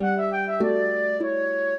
flute-harp
minuet14-4.wav